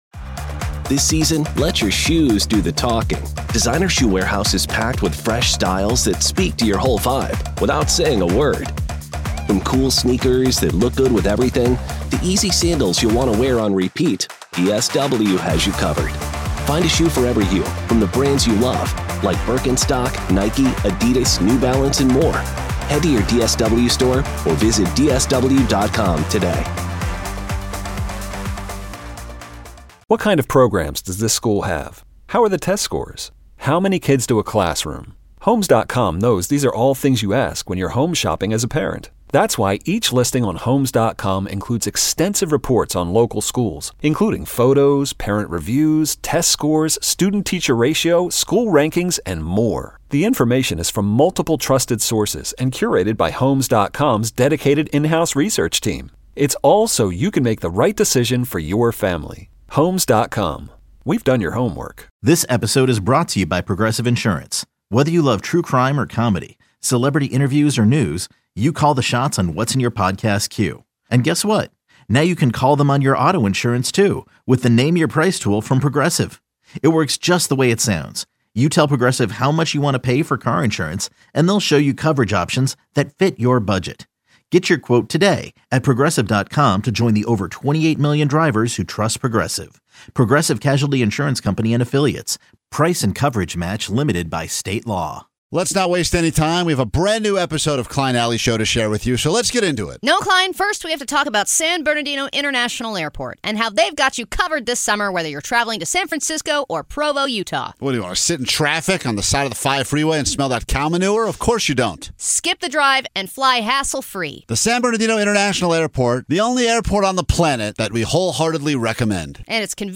With a loyal, engaged fanbase and an addiction for pushing boundaries, the show delivers the perfect blend of humor and insight, all while keeping things fun, fresh, and sometimes a little bit illegal.